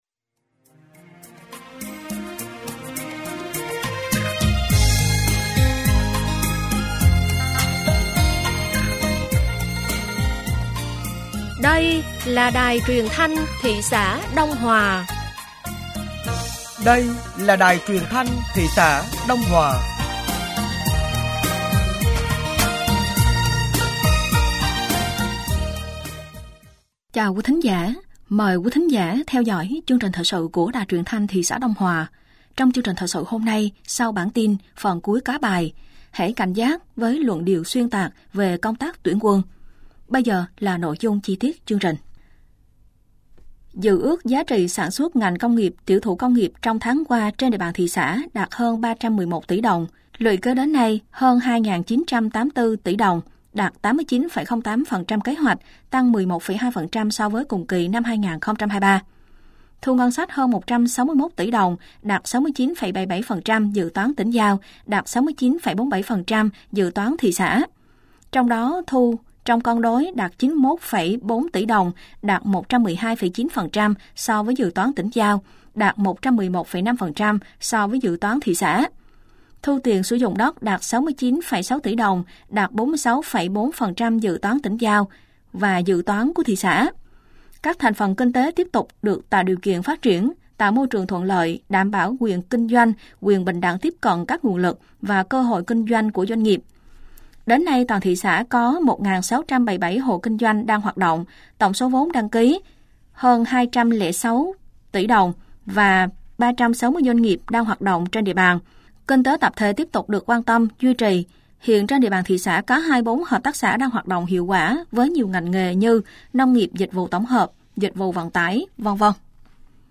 Thời sự tối ngày 10 và sáng ngày 11 tháng 12 năm 2024